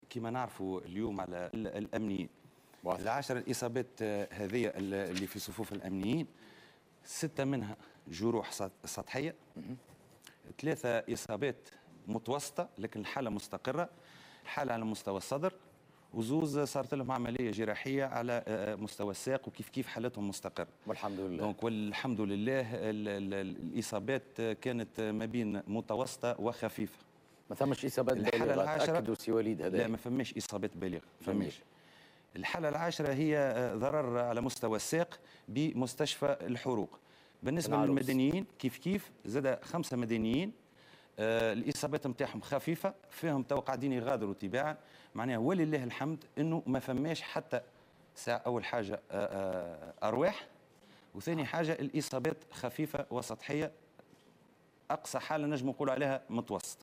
إصابة 10 أمنيين و 5 مدنيين (تصريح